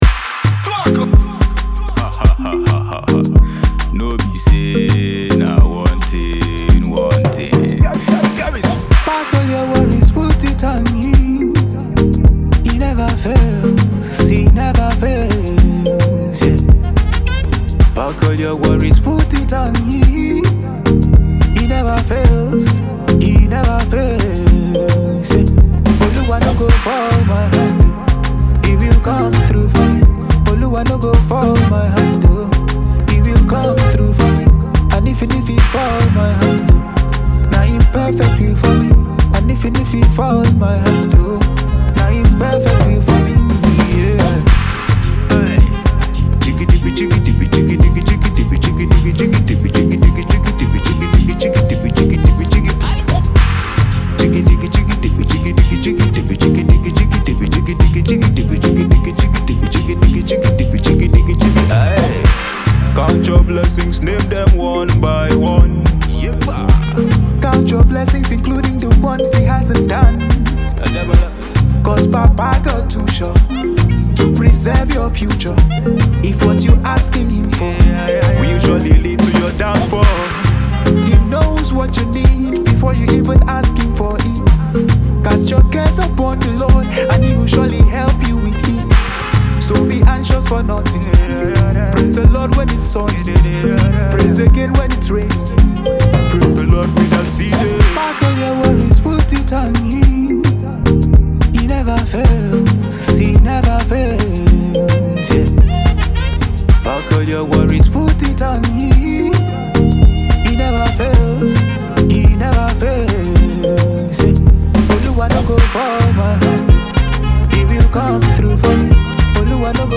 afrobeat hit song
bass guitar
saxophone